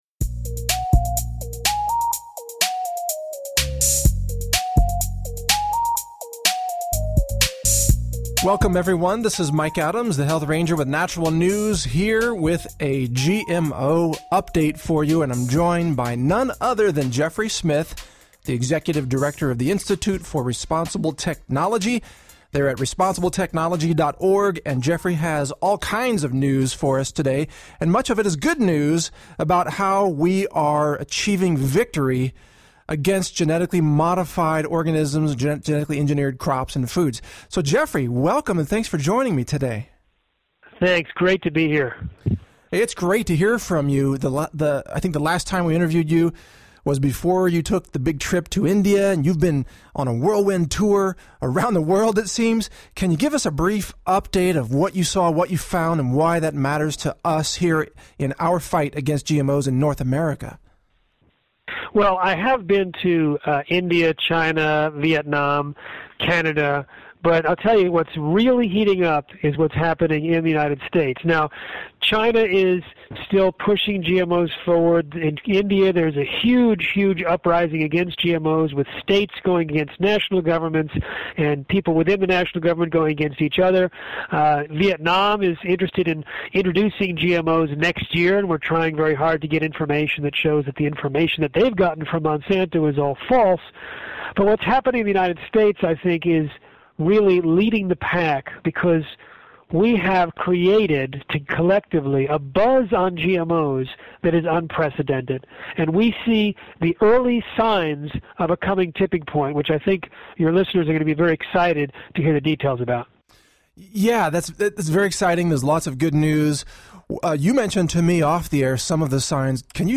Highlights from the interview